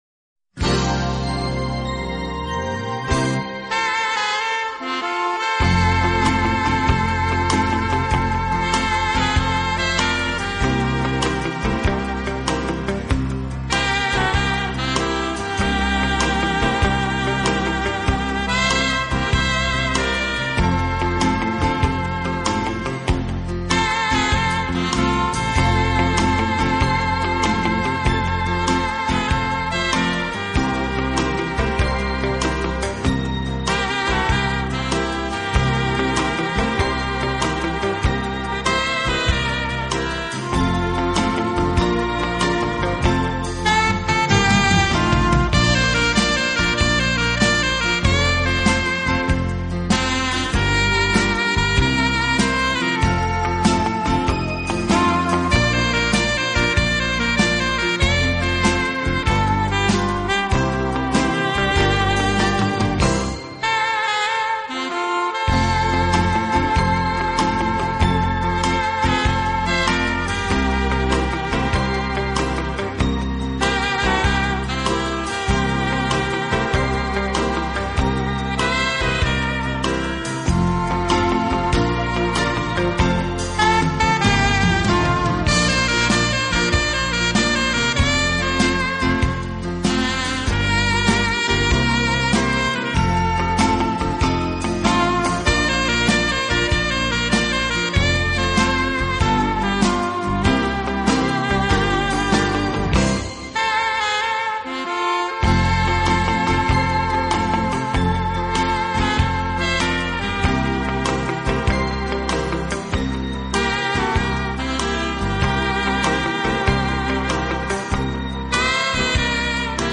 的轻音乐团，以萨克斯管为主，曲目多为欢快的舞曲及流行歌曲改编曲。演奏
轻快、柔和、优美，带有浓郁的爵士风味。